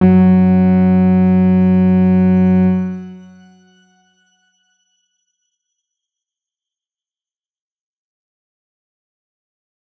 X_Grain-F#2-pp.wav